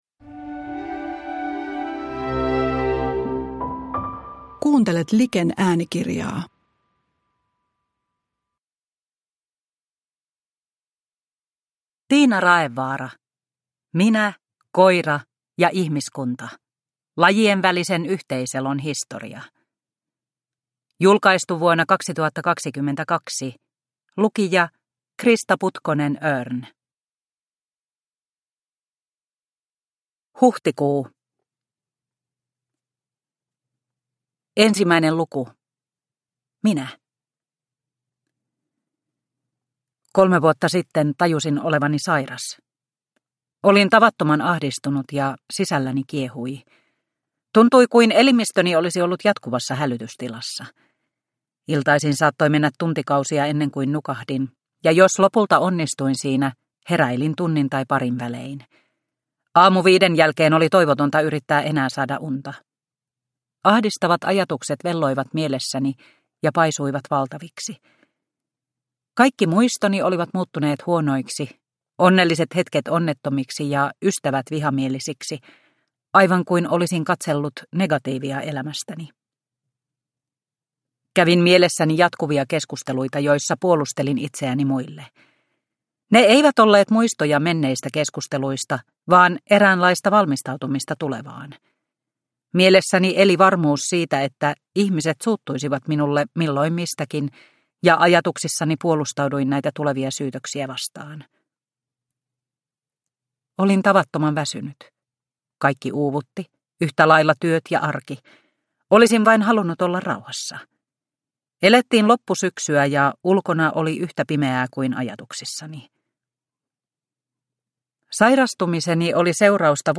Minä, koira ja ihmiskunta – Ljudbok – Laddas ner